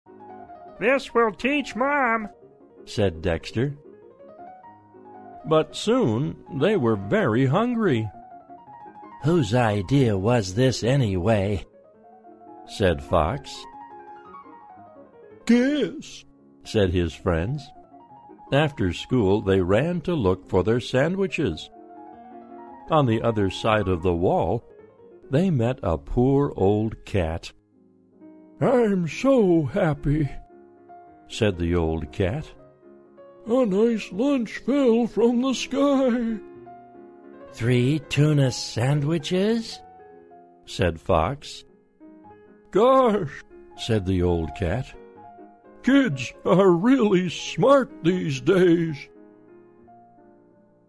在线英语听力室小狐外传 第19期:教训的听力文件下载,《小狐外传》是双语有声读物下面的子栏目，非常适合英语学习爱好者进行细心品读。故事内容讲述了一个小男生在学校、家庭里的各种角色转换以及生活中的趣事。